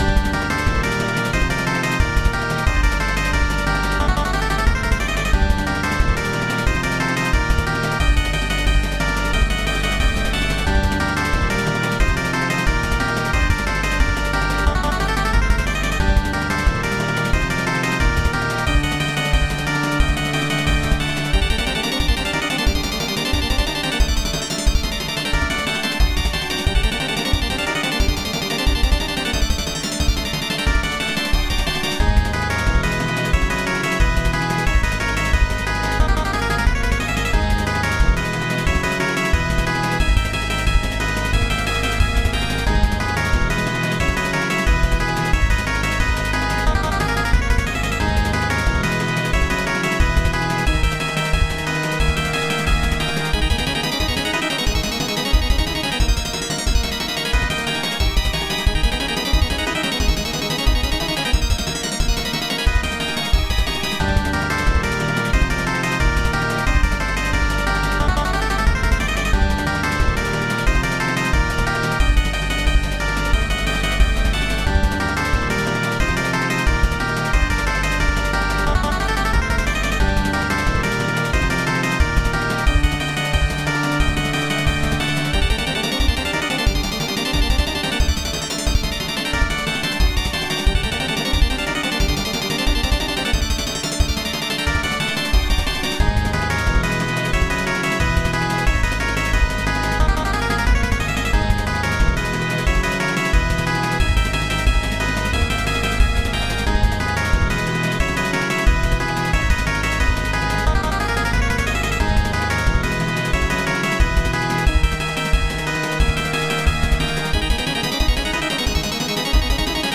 Random mid-boss theme from another abandoned project. This was from an older one, so the music was very much raw/unfinished.
It's pre-looped like I used to do before learning how to loop music in the engine I was using.
boss-theme.ogg